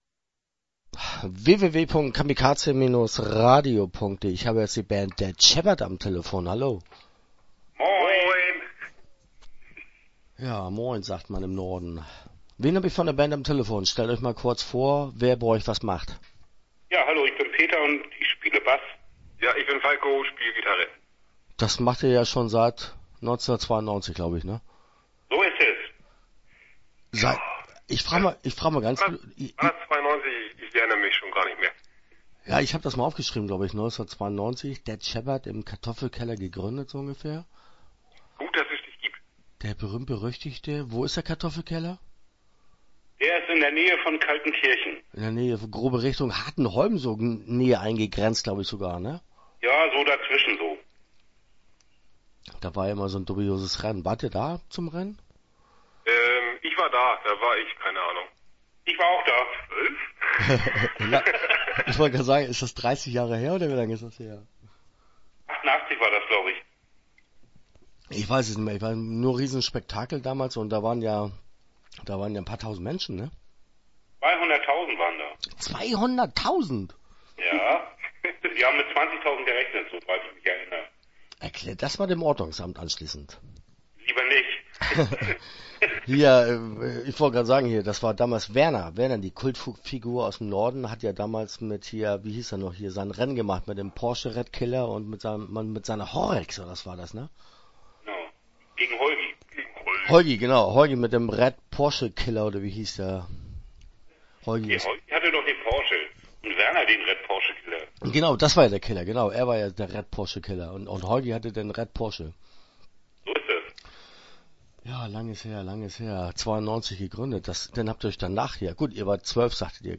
Dead Shepherd - Interview Teil 1 (11:29)